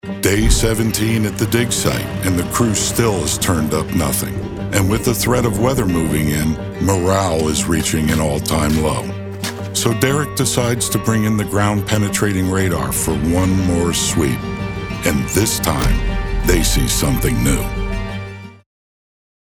A Uniquely Deep, Resonant and Relatable Voice Over Actor
I have a complete home studio with a RØDE NT1 5th Generation Large-Diaphragm Studio Condenser Microphone, a FocusRite 4th Generation 2i2 Audio Interface.
Documentary-1.mp3